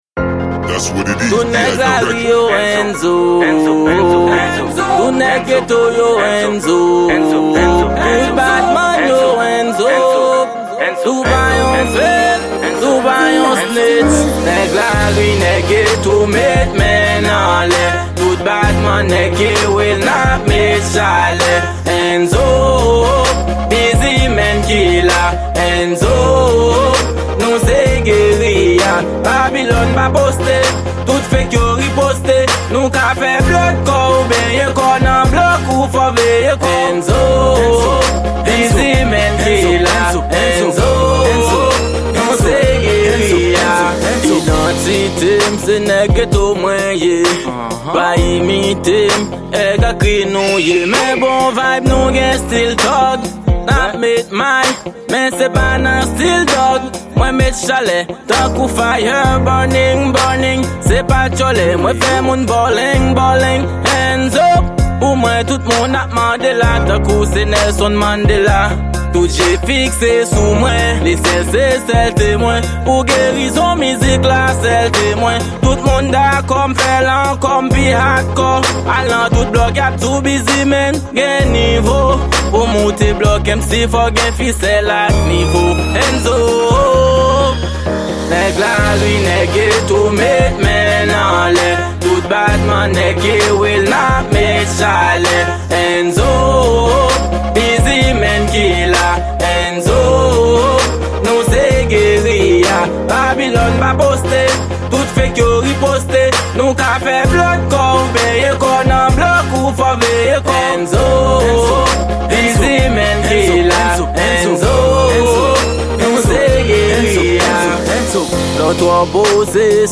Genre:Rap.